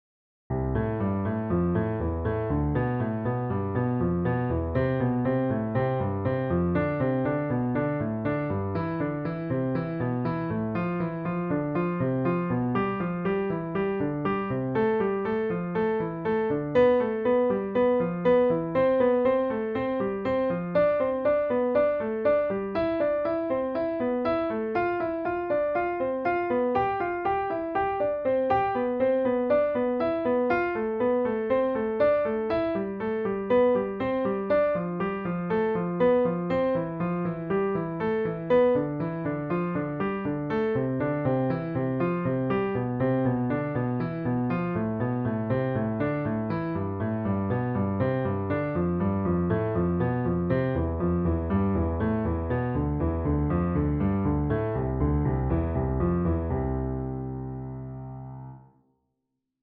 for Piano